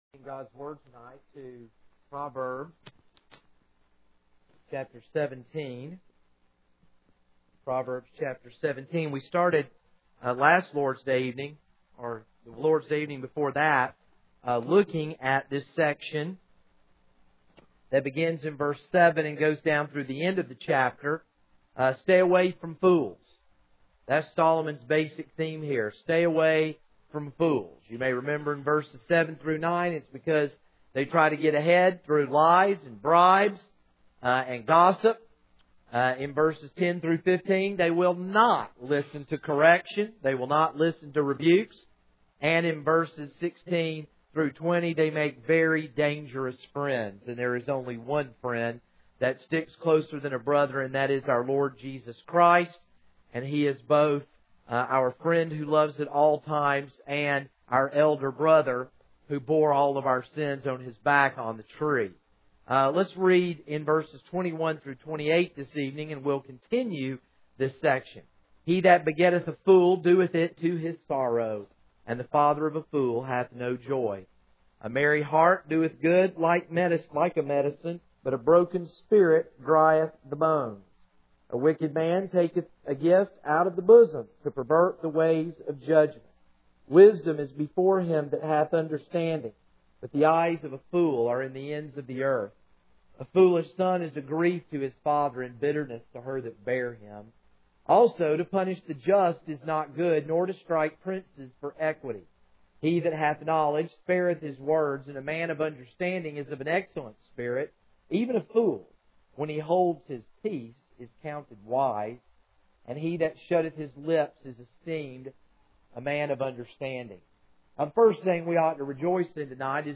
This is a sermon on Proverbs 17:21-28.